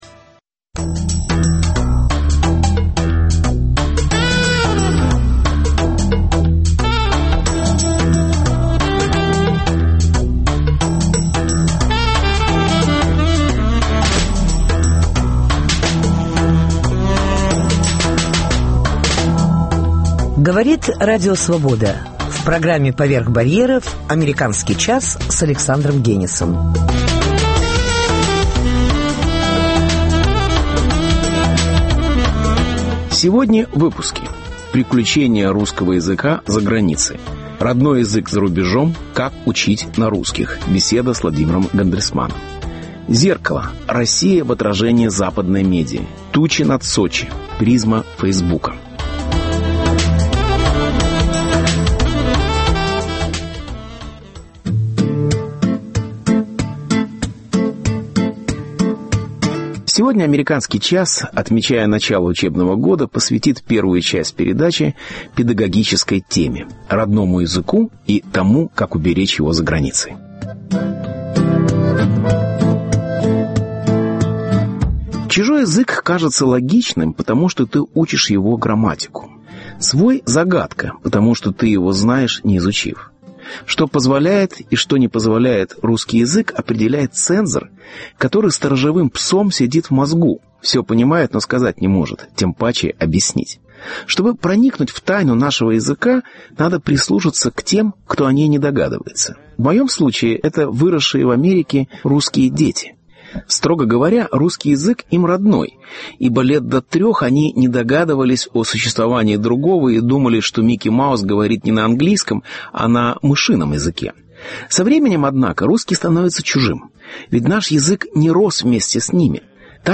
Приключения русского языка за границей - Родной язык за рубежом - Как учить на русских. Беседа